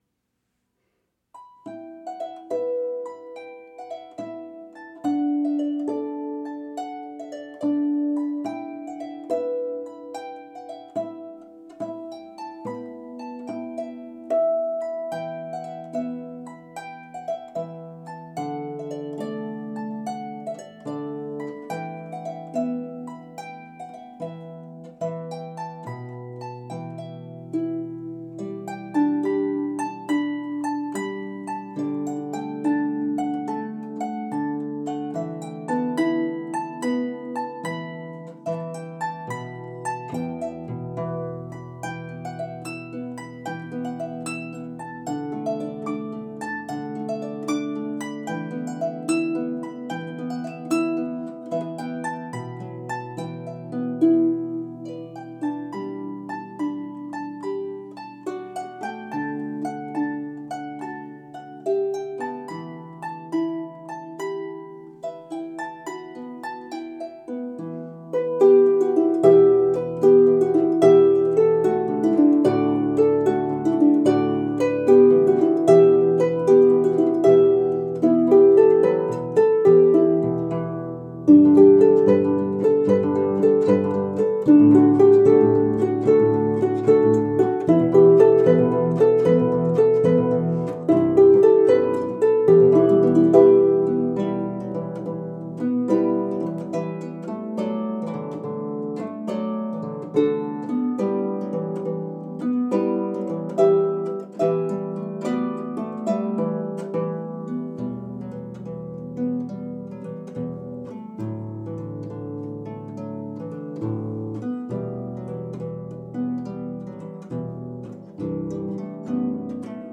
Irish tunes for solo lever or pedal harp
Harp Type